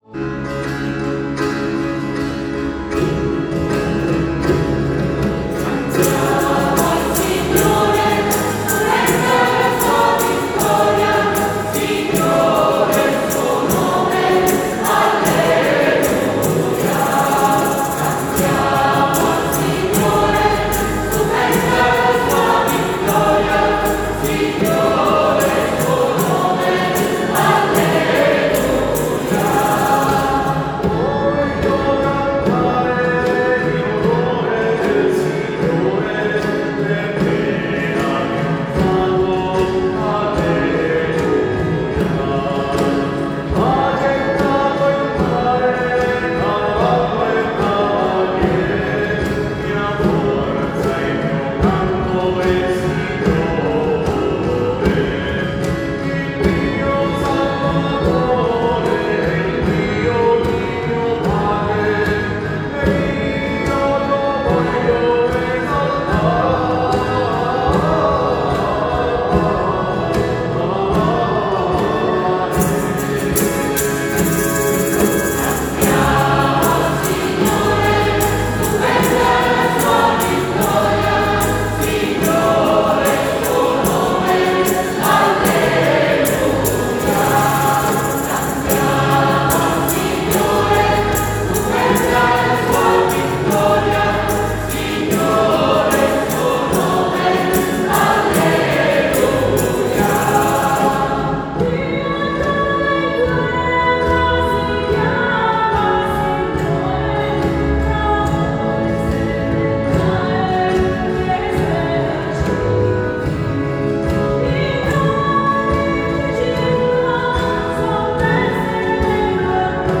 19 aprile 2025 - Notte di Pasqua
Organo
Chitarra
Cimbaletti
Bonghi